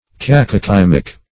Cacochymic \Cac`o*chym"ic\, Cacochymical \Cac`o*chym"ic*al\, a.